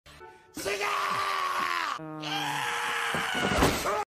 Play Inosuke Scream - SoundBoardGuy
Play, download and share Inosuke scream original sound button!!!!
inosuke-scream.mp3